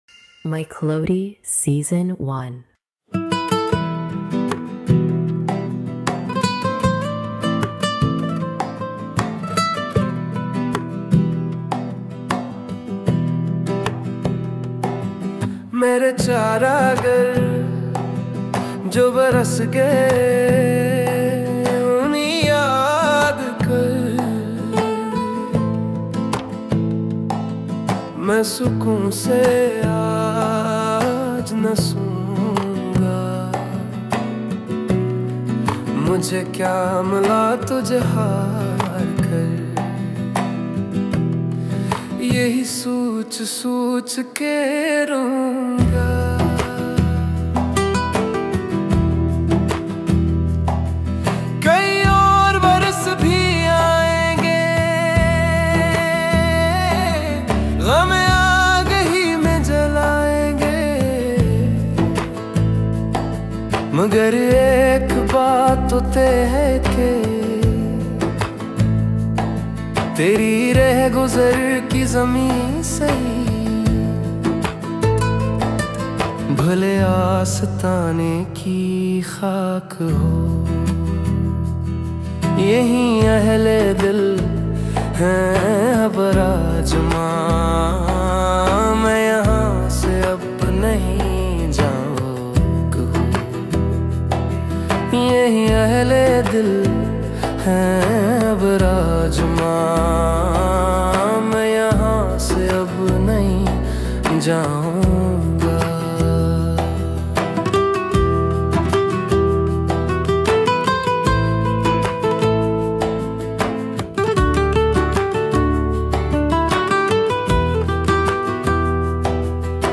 •  Lyrics born from the heart, melodies crafted with AI.